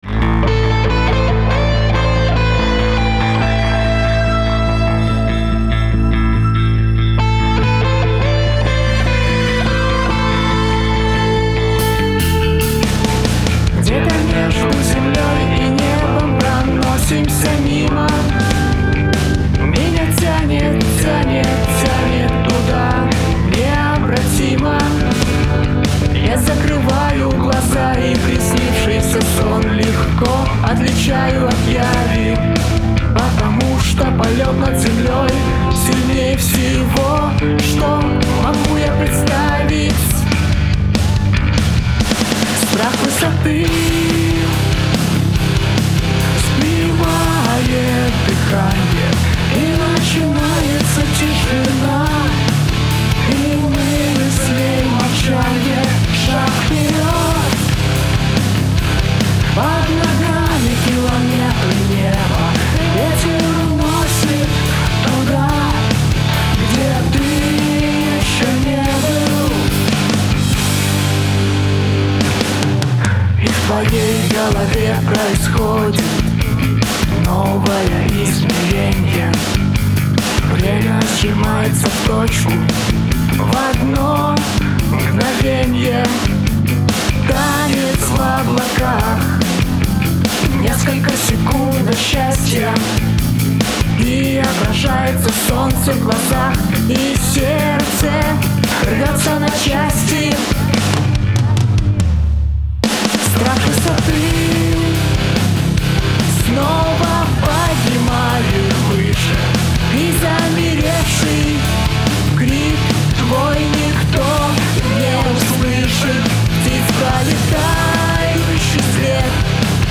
Для данного стиля вот такой вот перегруз это норма?